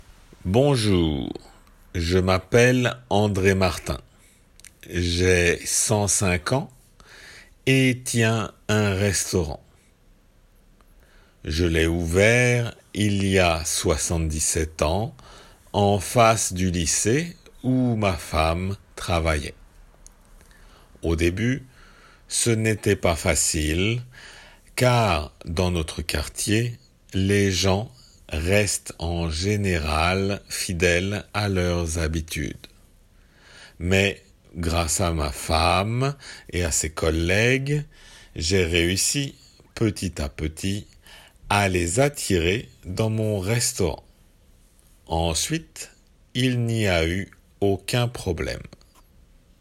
普通の速さで